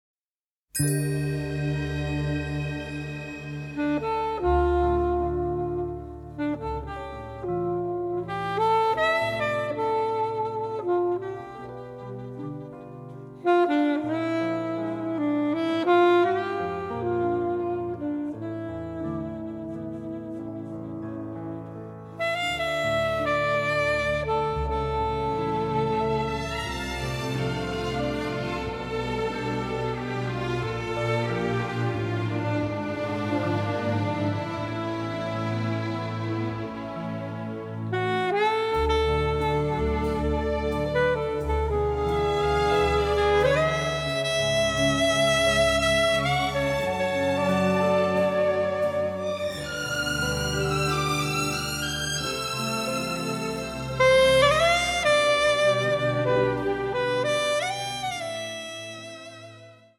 noir score
record the music in Paris